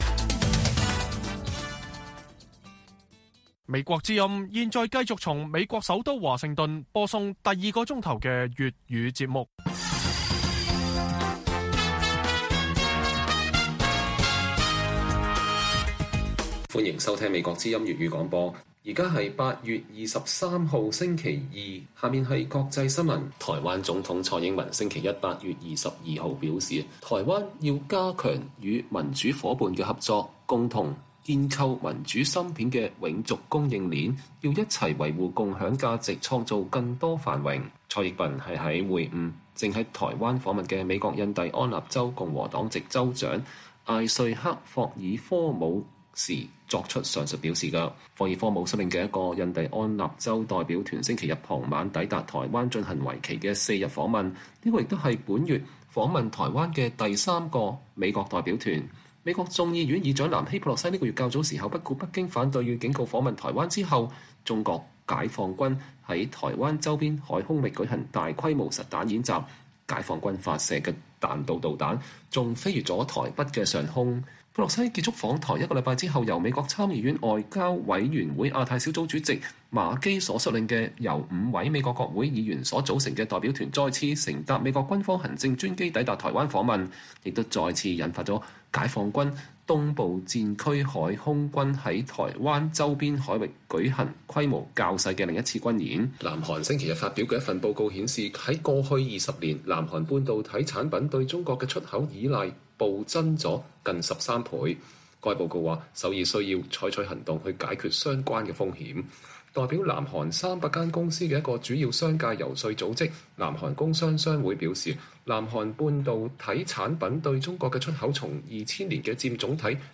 北京時間每晚10－11點 (1400-1500 UTC)粵語廣播節目。內容包括國際新聞、時事經緯、英語教學和社論。